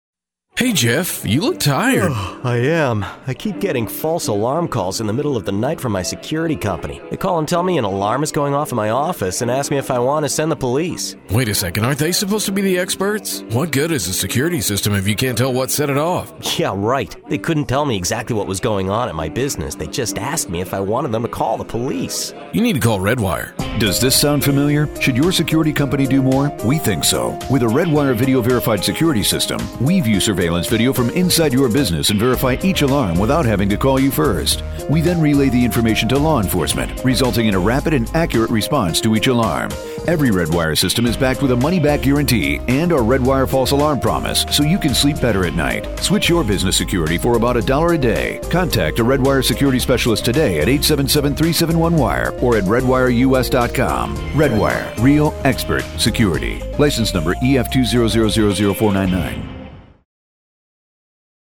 Redwire Security Radio Ad
2 Male character script with announcer voice-over